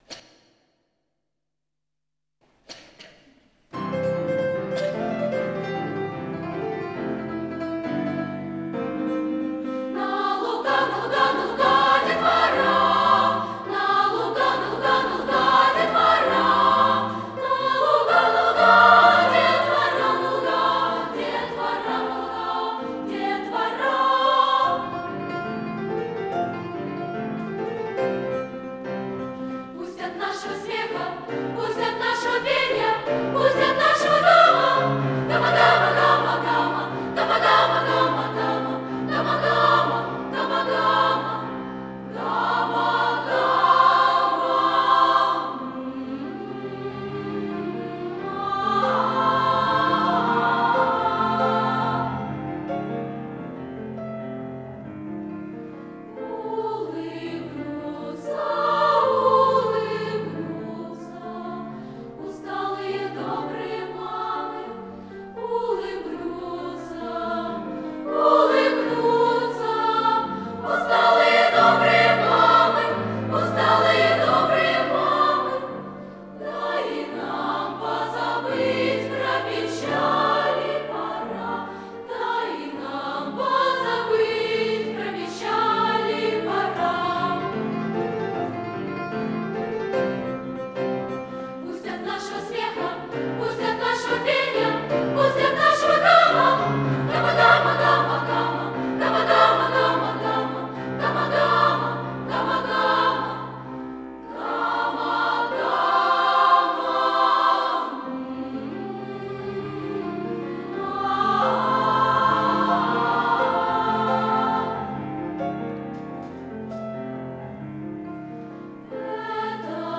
2. «хор Глазовчанка – Тугаринов Ю., сл. М. Джалиля “Цветы”» /